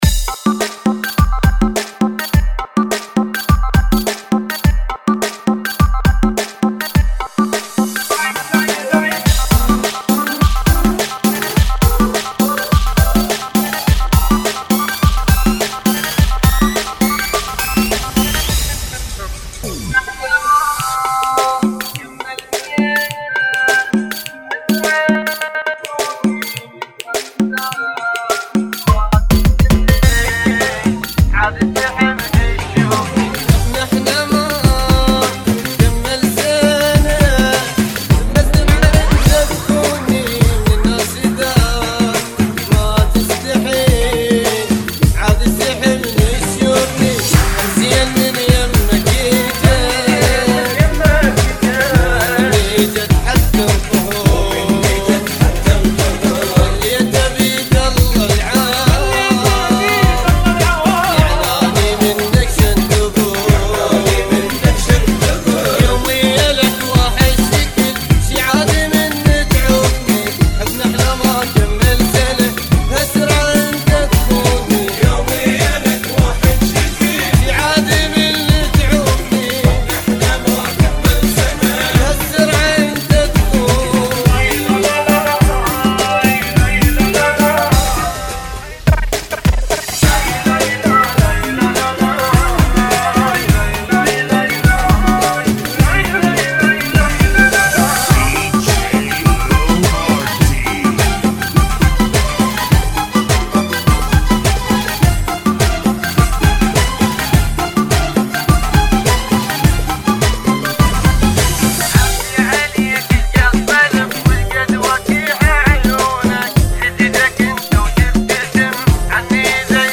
Funky ( Bbm 104